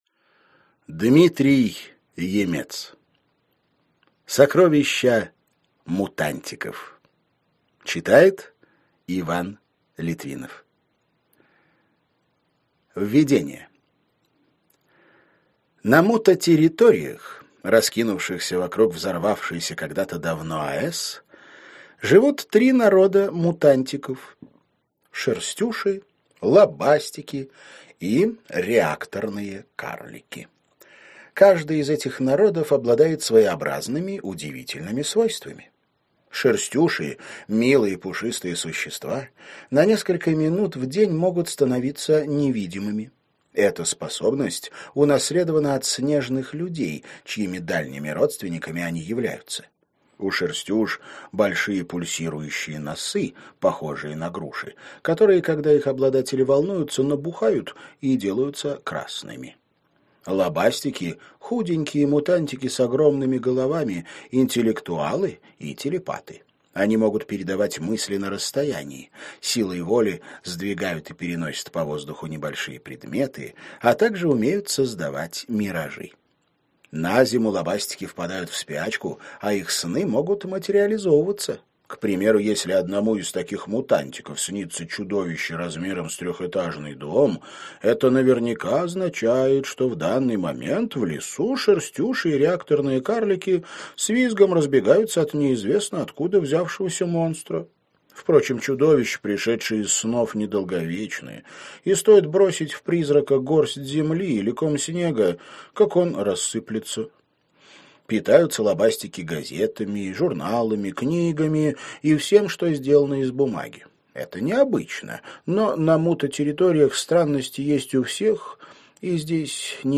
Аудиокнига Сокровища мутантиков | Библиотека аудиокниг